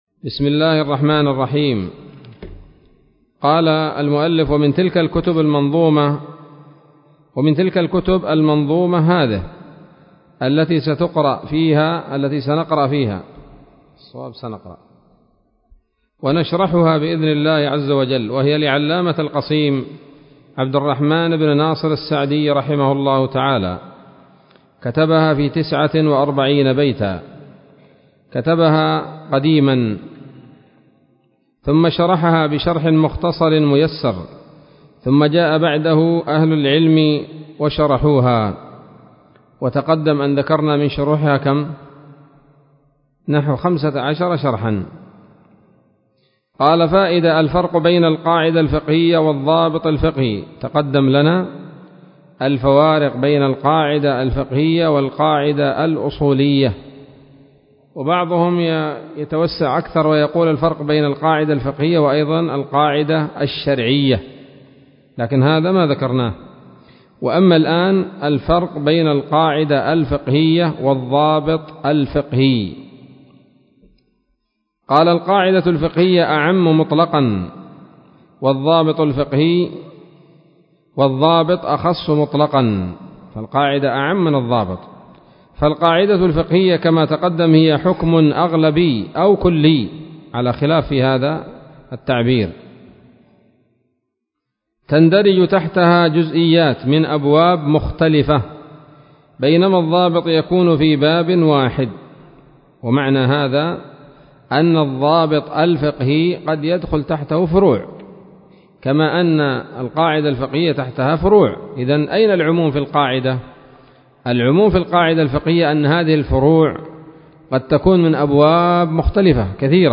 الدرس الرابع من الحلل البهية في شرح منظومة القواعد الفقهية